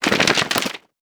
ihob/Assets/Extensions/CartoonGamesSoundEffects/Shake_v1/Shake_v4_wav.wav at master
Shake_v4_wav.wav